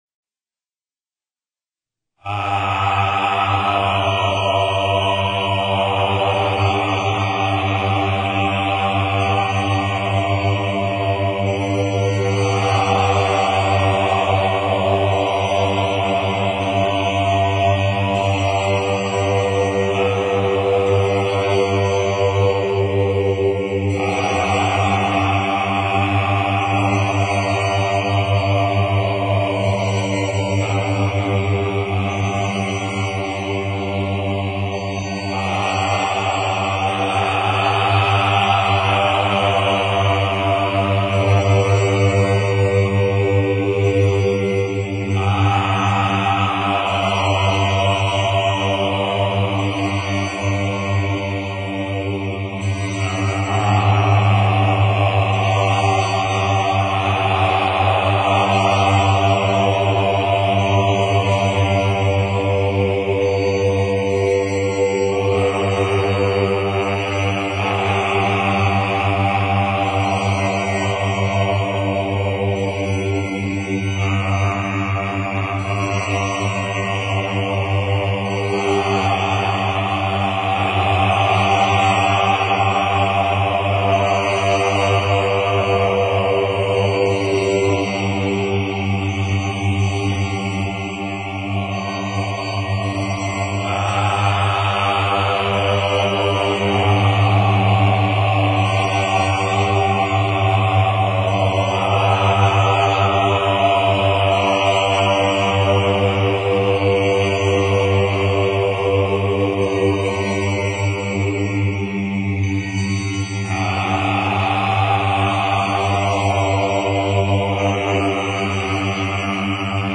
Gemeinsamer Mantra-Gesang (Gruppen-Chant)
aum_mantra.mp3